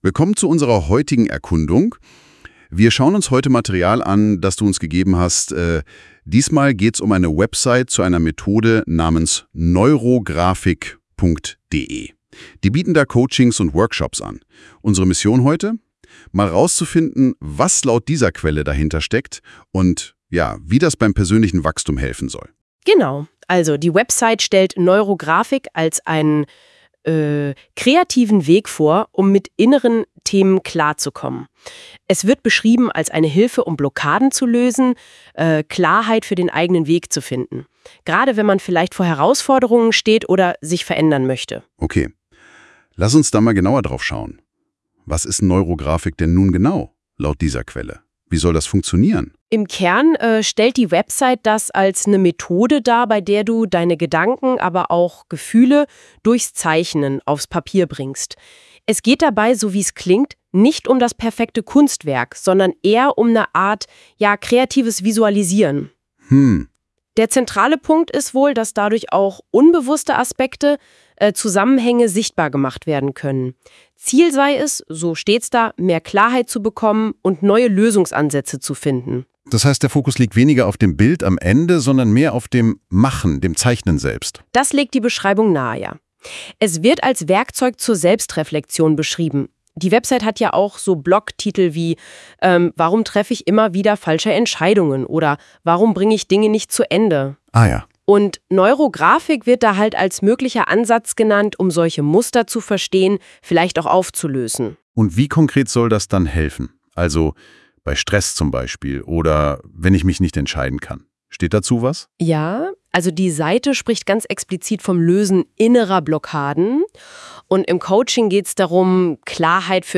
Zum Beispiel habe ich mit NotebookLM experimentiert und mir probeweise einen KI-generierten Dialog über meine Website erstellen lassen.
Audiozusammenfassung zu NeuroGraphik von NotebookLM